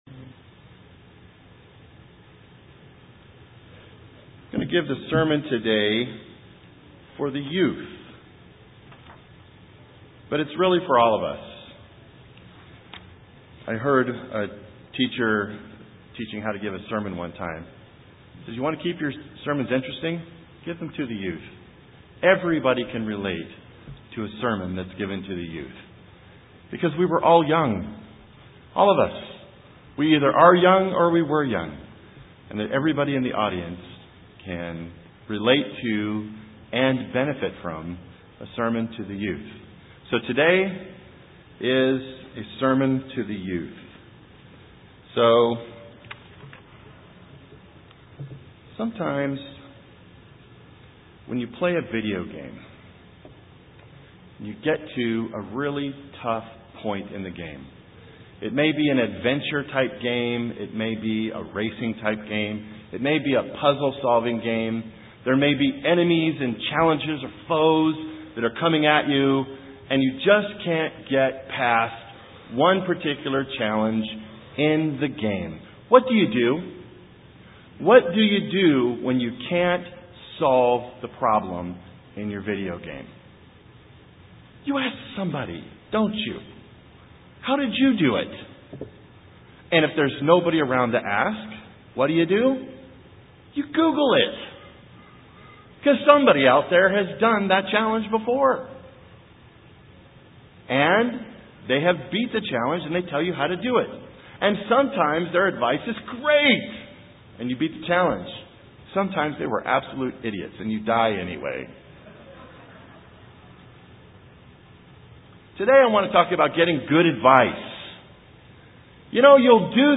In this sermon we learn how to get good advice.